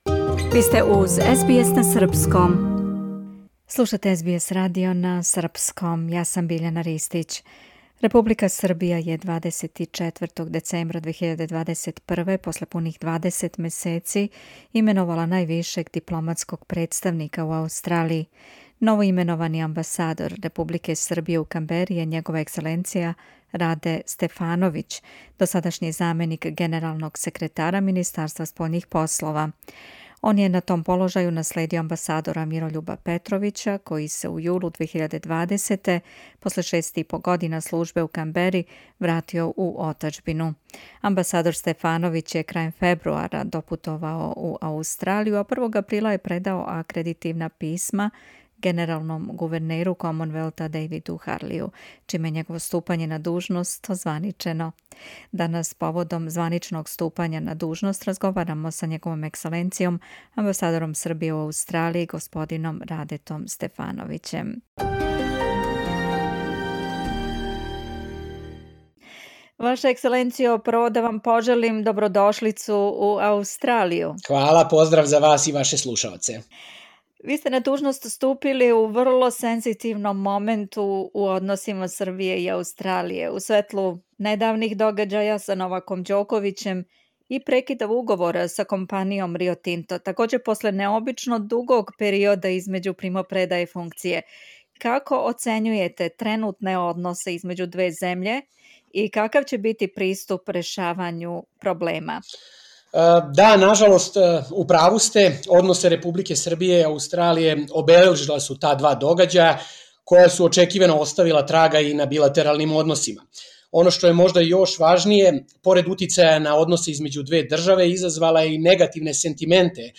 Поводом званичног ступања на дужност доносимо разговор са Њ.Е. амбасадором Србије у Аустралији, г. Радетом Стефановићем.
interview_srb_ambassador.mp3